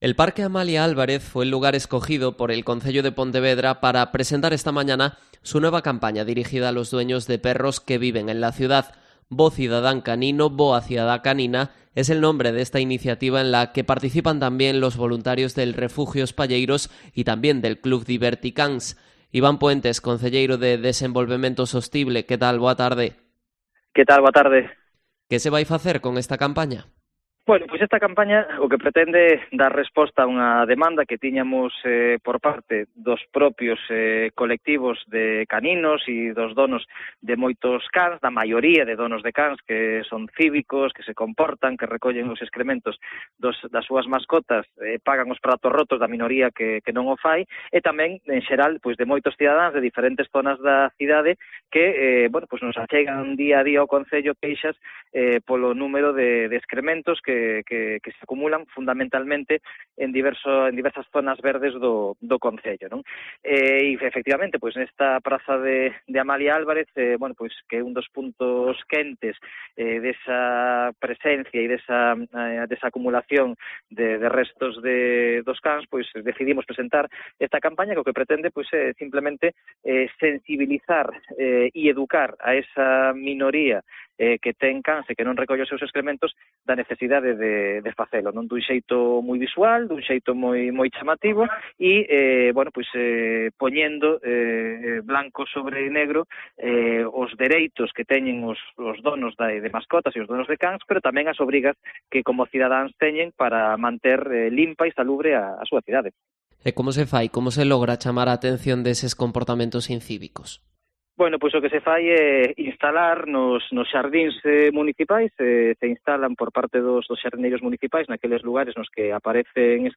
Entrevista a Iván Puentes, concejal de Desenvolvemento Sostible en Pontevedra